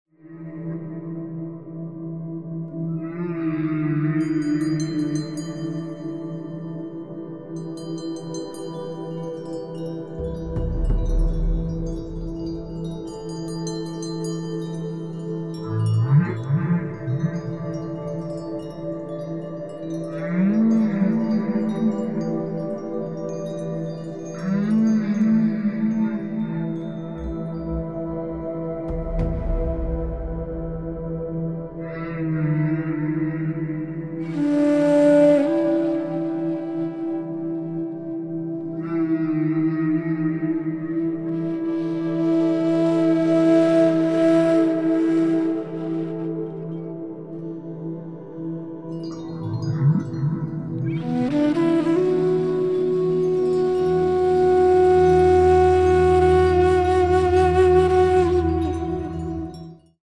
Музыка для медитации, сеансов терапии и релаксации